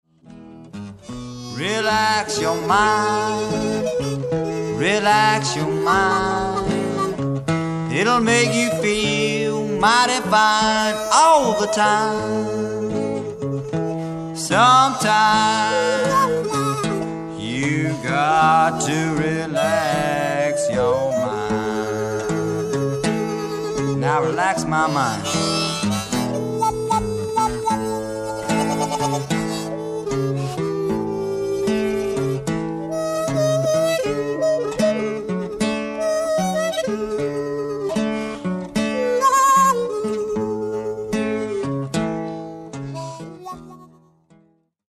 60年代の「コーヒーハウス」文化を匂わす温もりのある録音が聴き所。
ハーモニカ